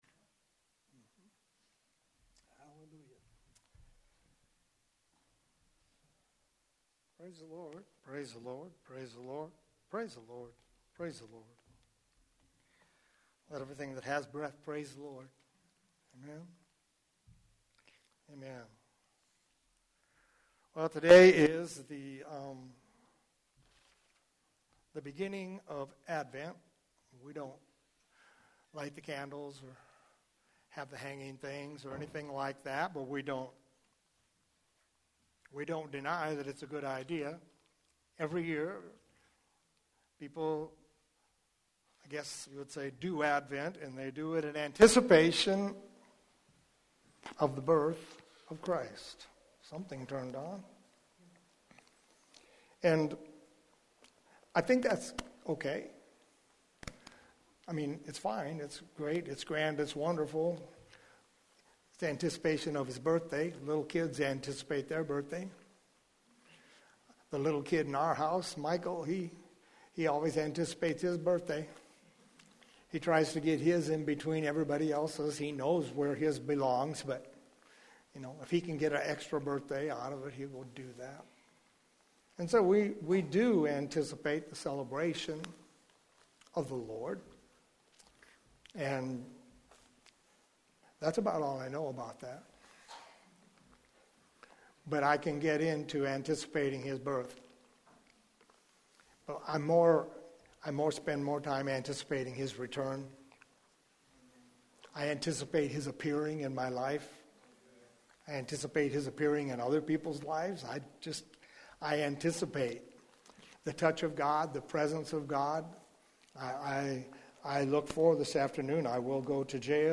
Here you’ll find a selection of audio recordings from Hosanna Restoration Church.